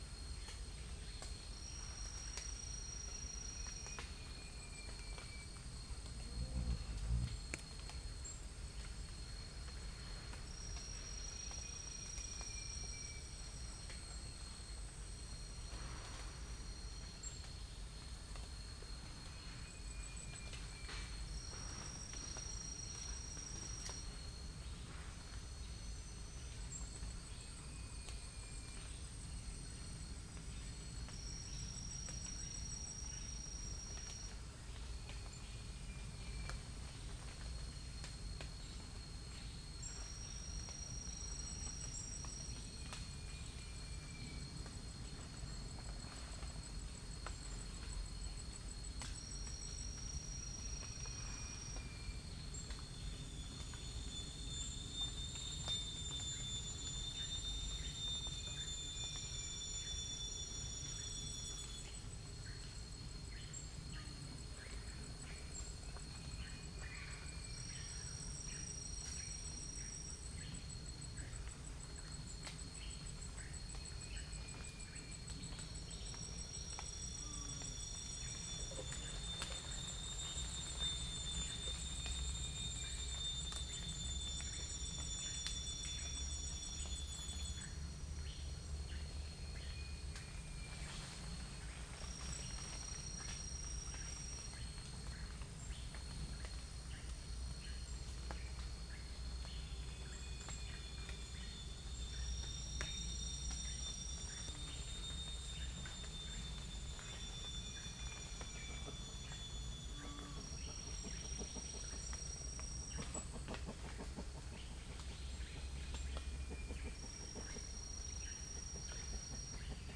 Oriolus xanthonotus
Orthotomus sericeus
Trichastoma malaccense
Prinia familiaris